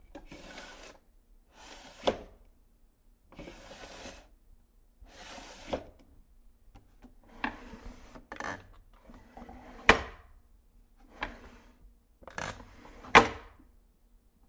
随机的" 抽屉小木头滑轨打开关闭
描述：抽屉小木滑动打开close.flac
Tag: 打开 关闭 幻灯片 抽屉